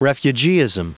Prononciation du mot refugeeism en anglais (fichier audio)
Prononciation du mot : refugeeism